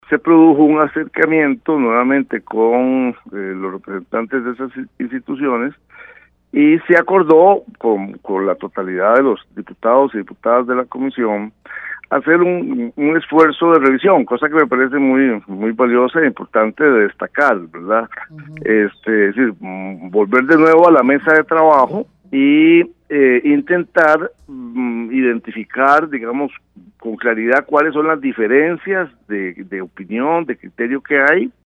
El diputado y jefe de fracción oficialista, Victor Morales indicó que el Organismo de Investigación Judicial (OIJ), la Fiscalía y el ICD deben ser tomados en cuenta por ser los futuros aplicantes de la ley.